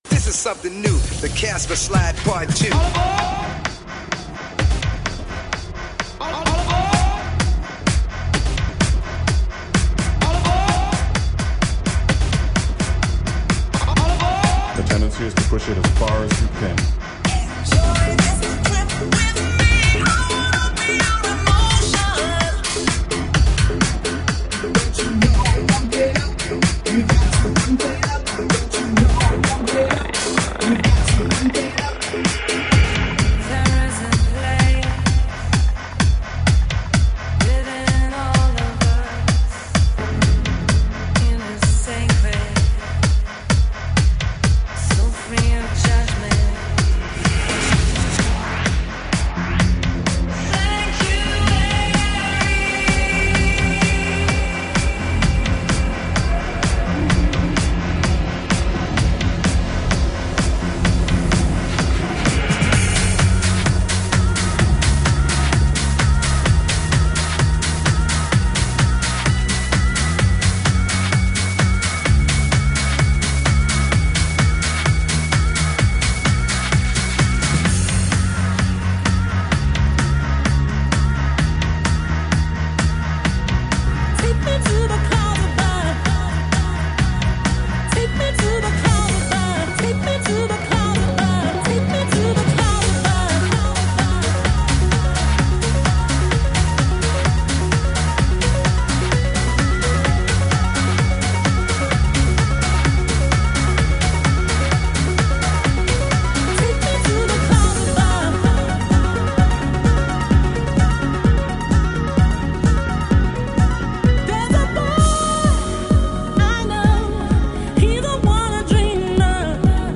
First mix down (EDIT)  (2:50) 80kbps 32.0 Khz   (1.7 Mb) ( !
01: 128 - IntroBeats